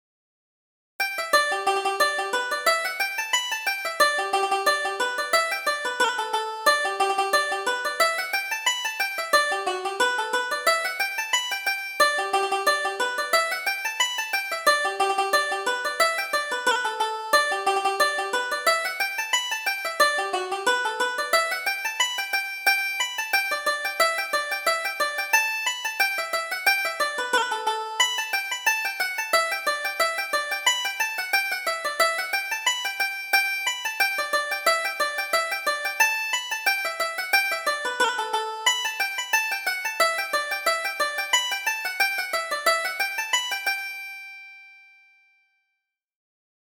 Reel: Take Your Choice